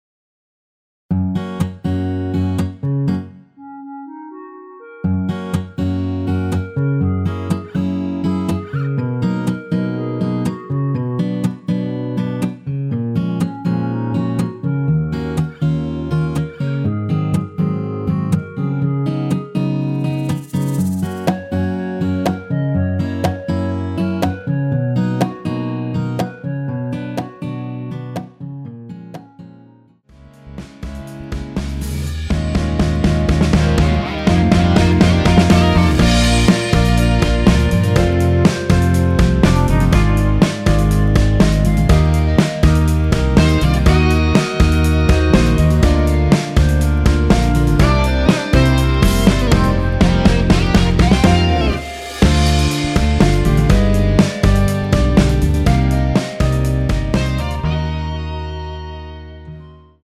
원키에서(-1)내린 멜로디 포함된 MR입니다.
전주없이 노래가 시작 되는 곡이라 전주 1마디 만들어 놓았습니다.(미리듣기 참조)
Gb
앞부분30초, 뒷부분30초씩 편집해서 올려 드리고 있습니다.
중간에 음이 끈어지고 다시 나오는 이유는